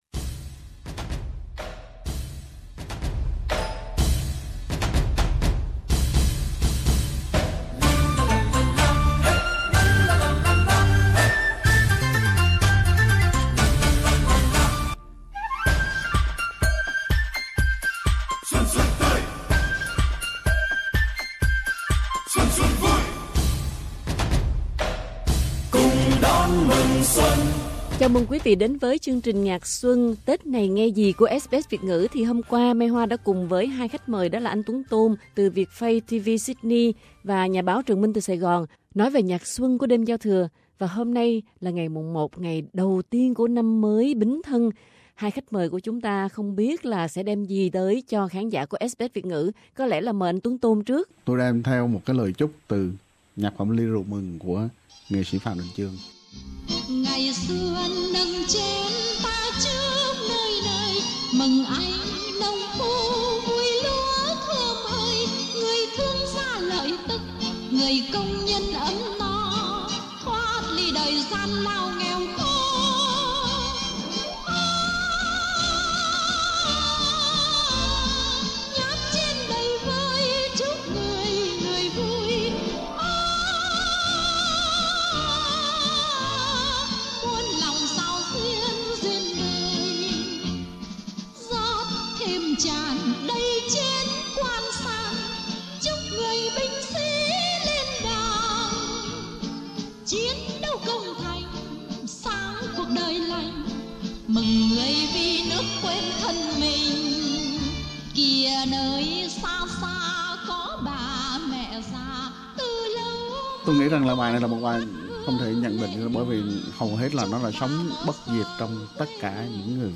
Ngày đầu năm mới SBS Việt ngữ sẽ mang đến cho quý vị những bài hát xuân rộn rã và không kém phần tha thiết yêu người yêu đời yêu quê hương đất nước với những lời chúc lành cho tất cả mọi người.